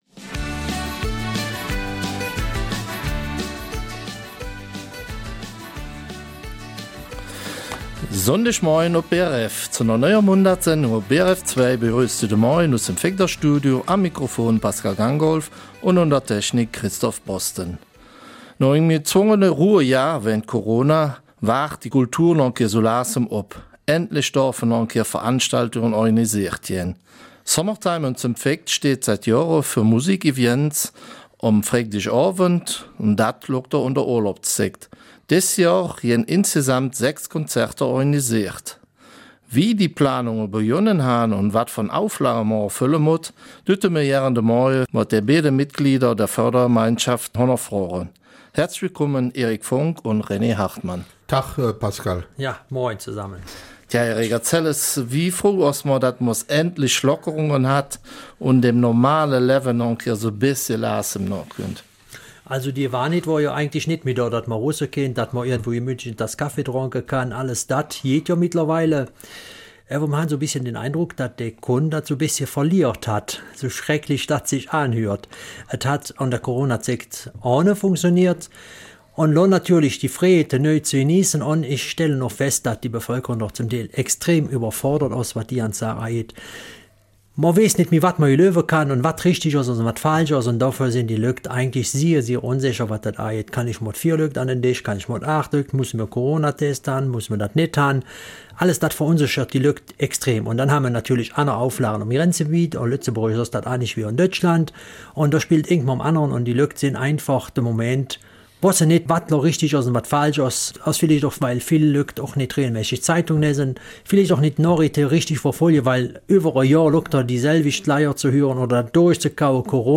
Eifeler Mundart: Summertime 2021